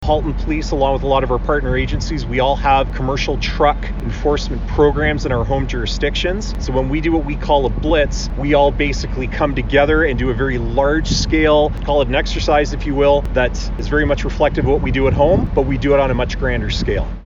Oct-6-CMV-Press-Conference-2.mp3